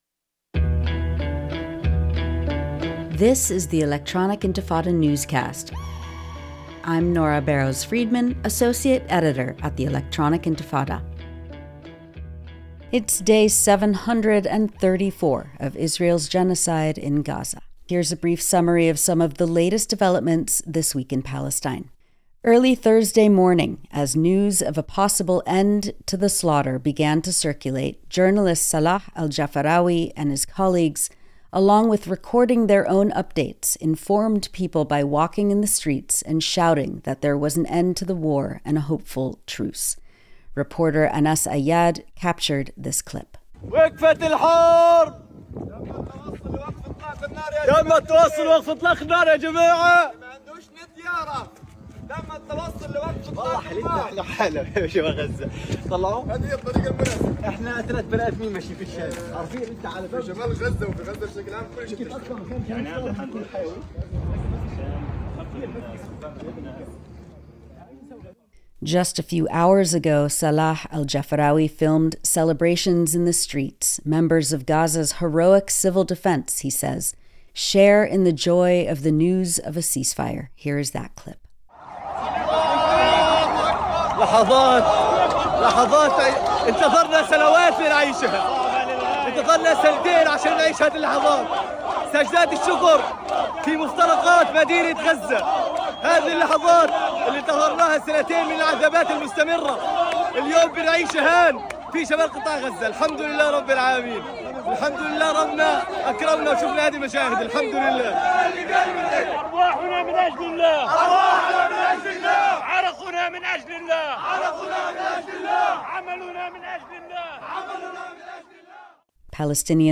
Newscast